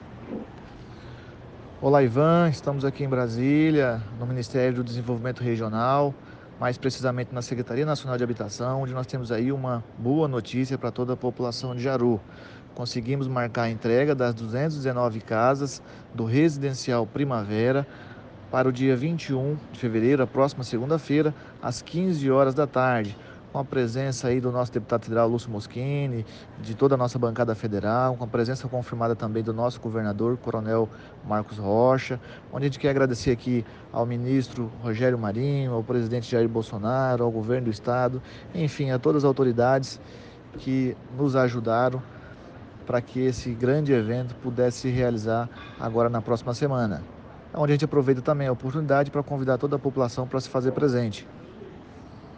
Ouça o áudio do prefeito Jevérson Lima, abaixo:
Prefeito Jéverson Lima em Brasília, DF.